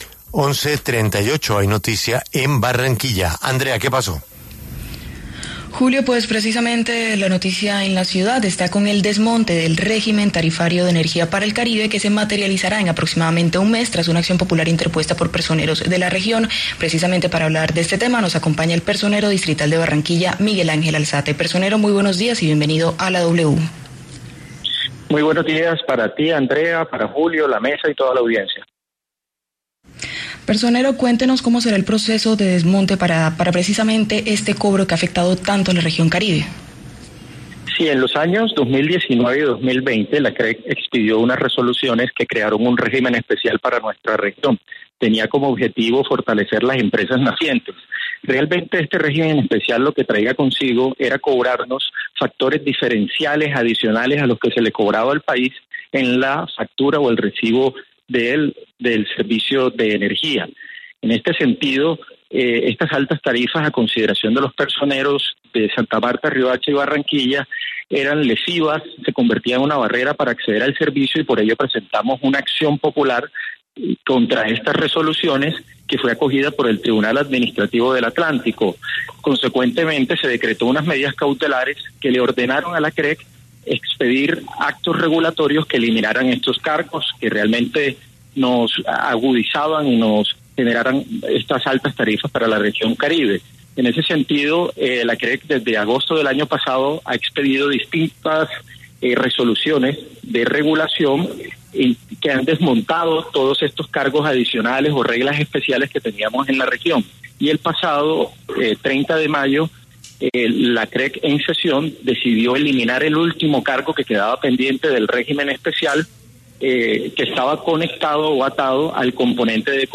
En conversación con La W, el personero de Barranquilla, Miguel Ángel Álzate, entregó detalles de la acción popular que logró el desmonte definitivo del régimen tarifario de energía para el Caribe, mismo que provocó un aumento desproporcionado de los cobros que se le hacían por el servicio a los usuarios de la región.